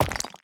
rock_destroyed_03.ogg